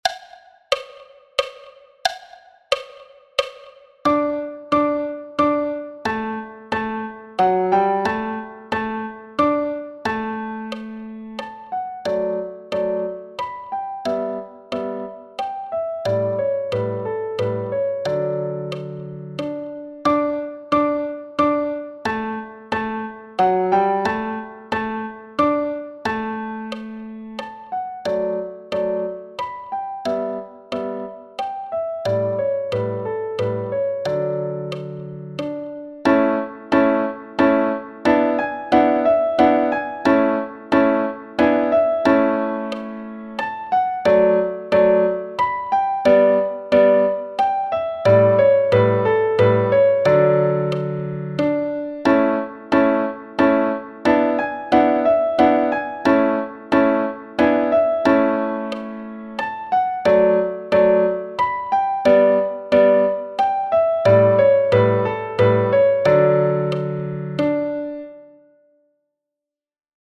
Danse allemande – piano à 90 bpm
Danse-allemande-piano-a-90-bpm.mp3